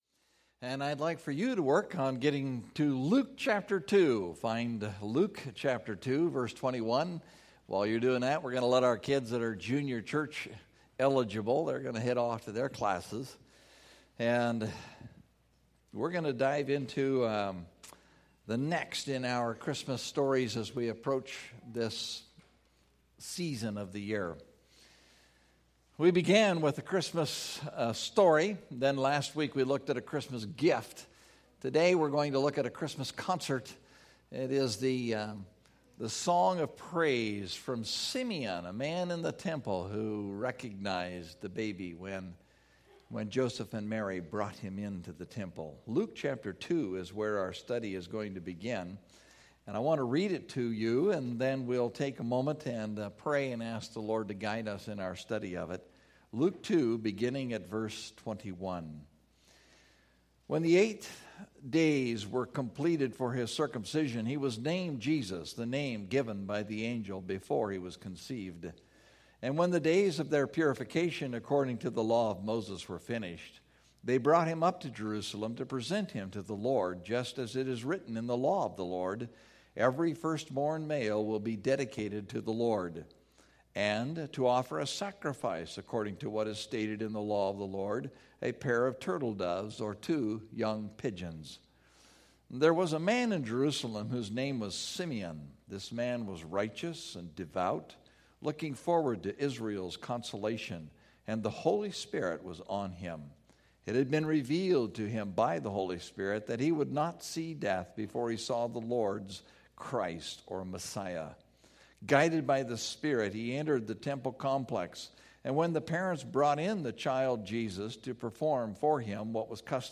A Christmas Concert (Luke 2:21-35) – Mountain View Baptist Church